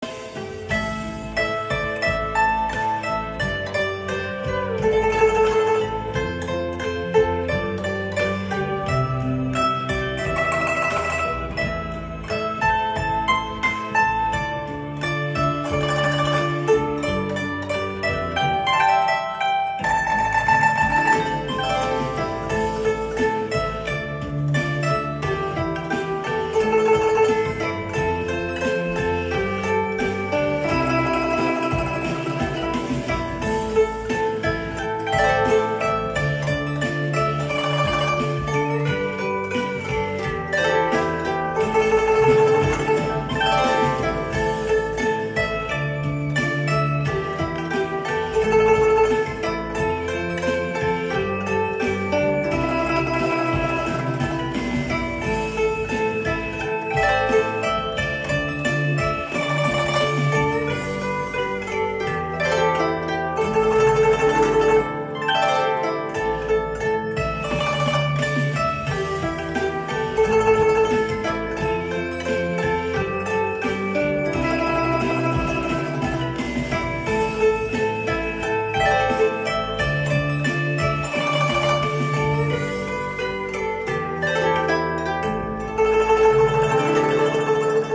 giai điệu buồn da diết.